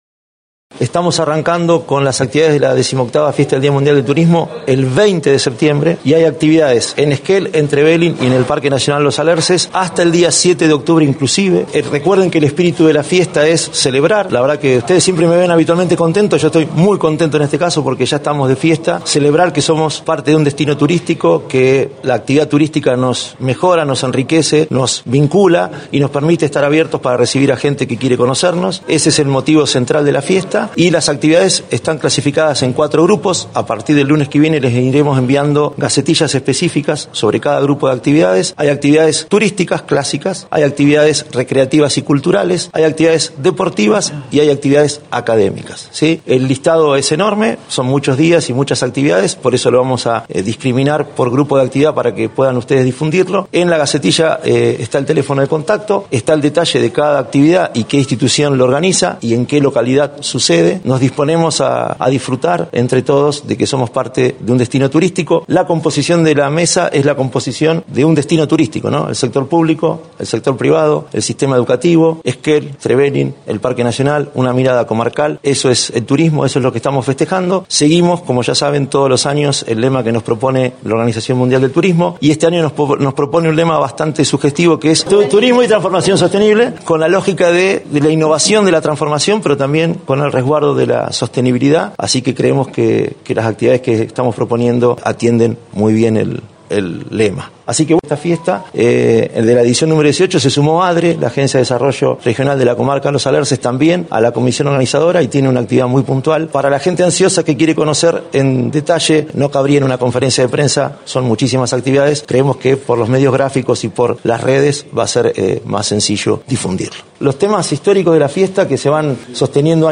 En conferencia de prensa se anunció la 18° edición de la Fiesta por el Día Mundial del Turismo.